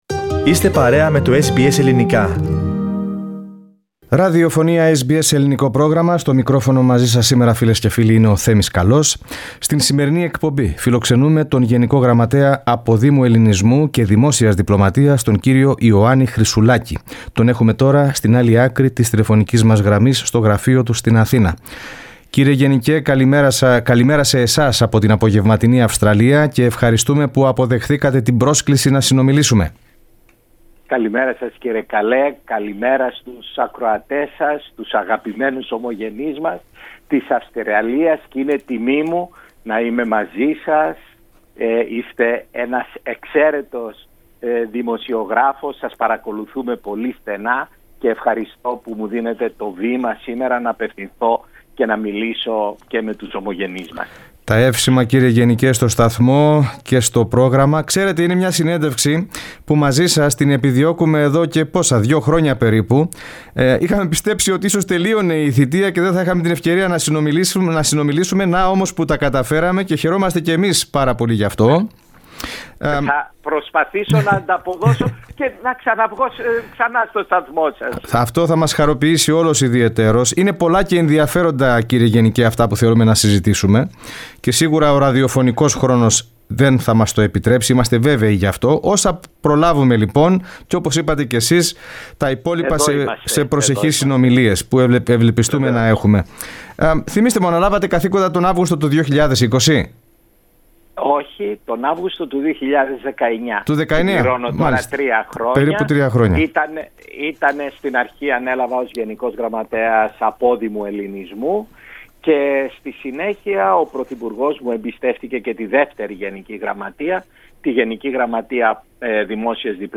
Ο Γενικός Γραμματέας Αποδήμου Ελληνισμού και Δημόσιας Διπλωματίας Γιάννης Χρυσουλάκης μίλησε στο SBS Greek για την ομογένεια της πολιορκούμενης Μαριούπολης, την ψηφιοποίηση υπηρεσιών στα προξενεία και τις εξελίξεις στην ψήφο αποδήμων.